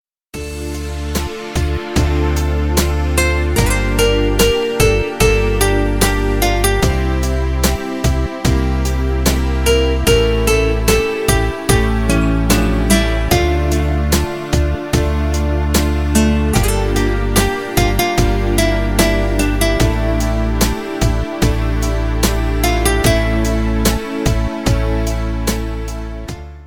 Спокойные рингтоны
Категория: спокойные